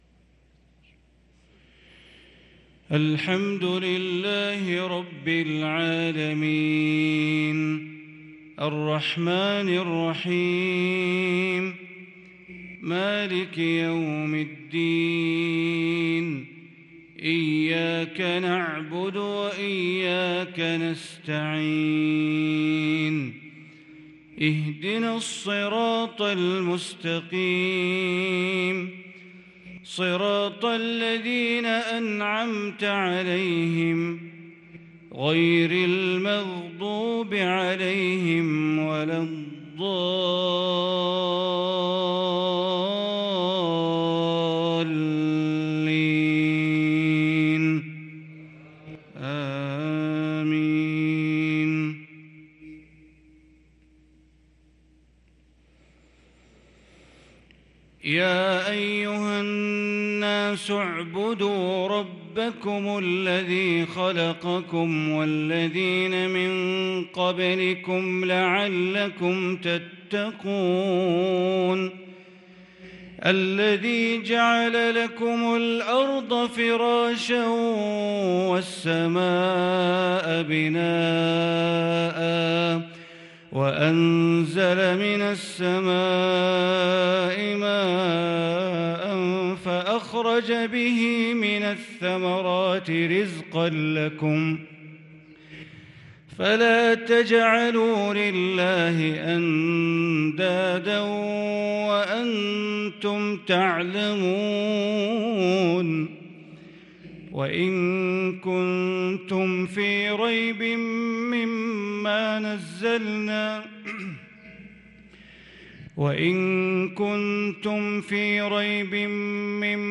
صلاة المغرب للقارئ بندر بليلة 13 جمادي الآخر 1443 هـ